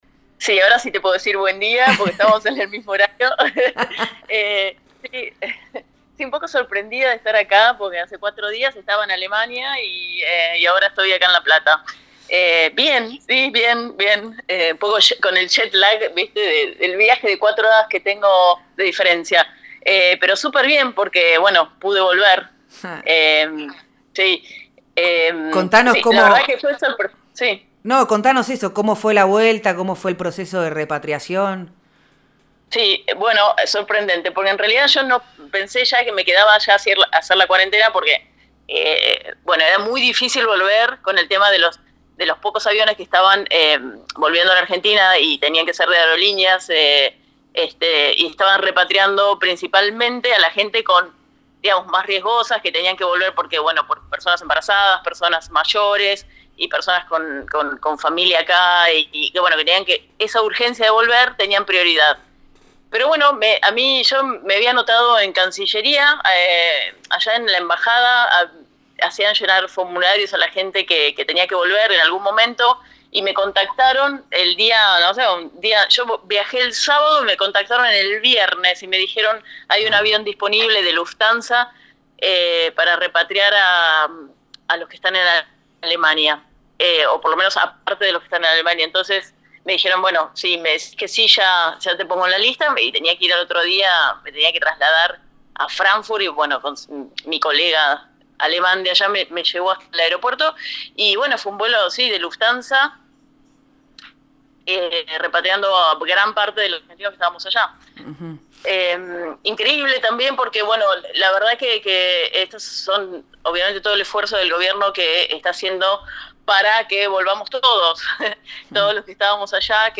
Escuchá el testimonio completo de cómo fue el proceso de repatriación y el viaje con destino a la Argentina: